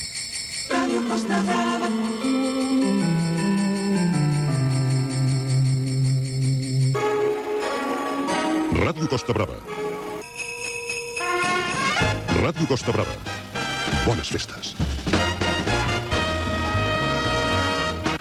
Indicatiu nadalenc de l'emissora